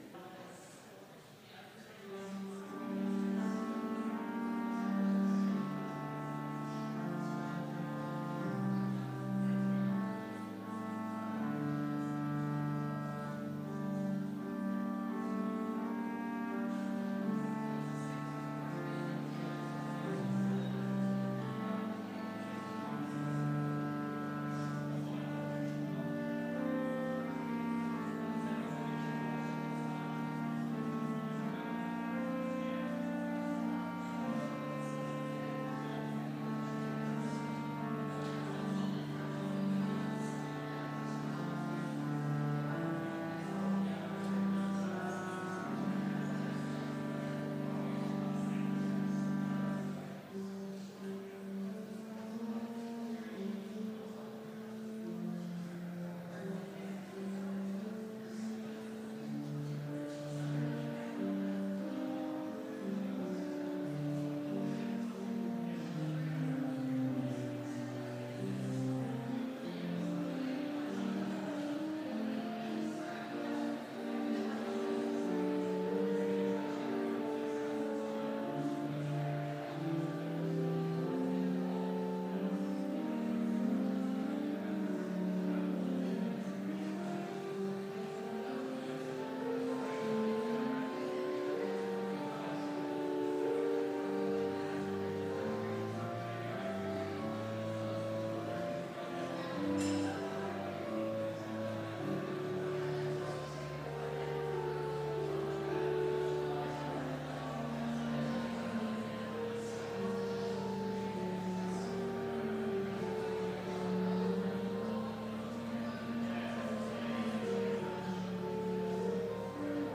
Complete service audio for Chapel - September 26, 2019